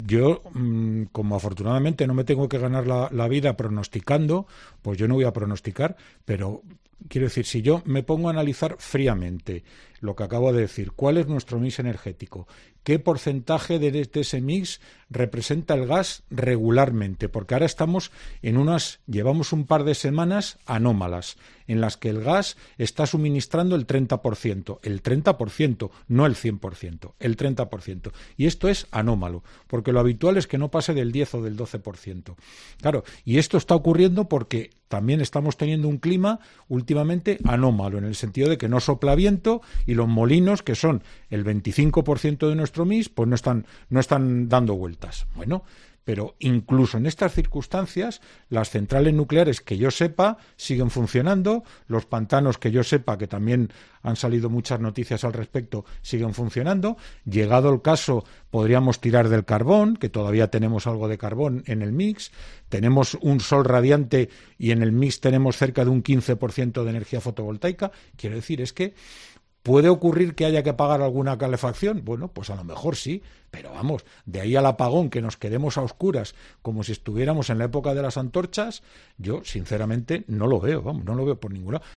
experto contactado por 'La Tarde' de COPE